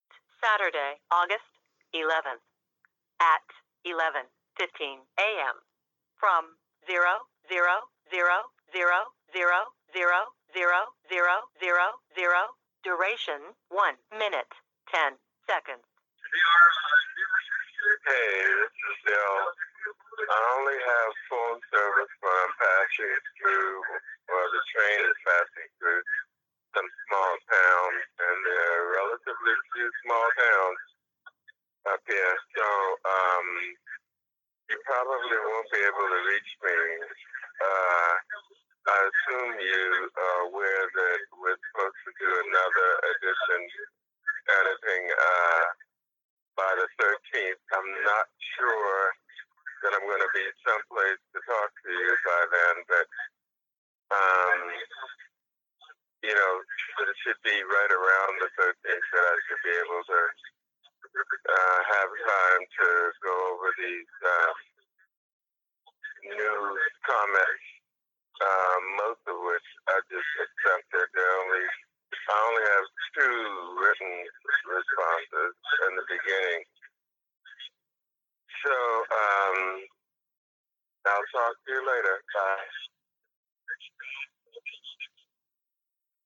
CDC grants for HIV prevention among Hispanic Americans in 1986 were imprecisely targeted because of lack of data on ethnicity and HIV. American College of Epidemiology Minority Affairs Committee Workshop, September 2015, Decatur, GA